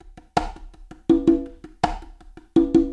Percu syncopée
Straight / 82 / 1 mes
CONGA1 - 82.mp3